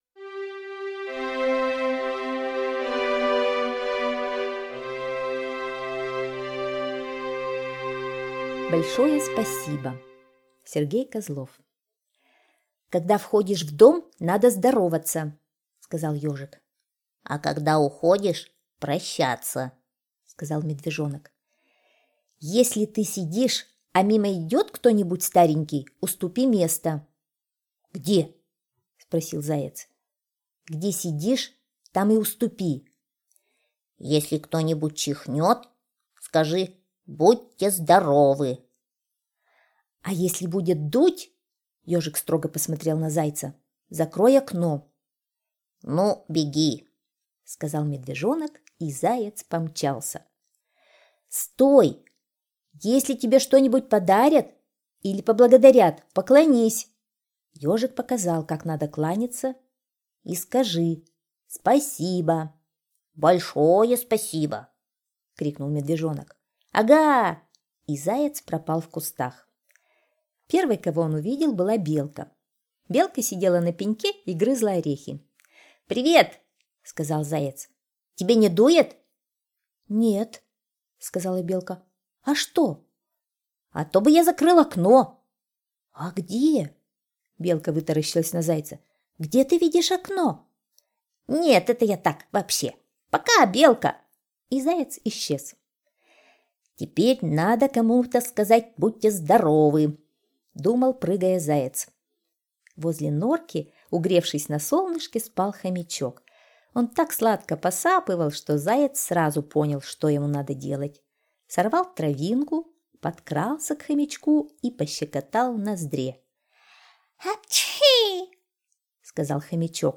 Слушайте Большое спасибо - аудиосказка Козлова С.Г. Сказка про то, как Ежик и Медвежонок вспоминали вежливые слова, а Заяц побежал по лесу их все сказать.